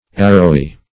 Arrowy \Ar"row*y\, a.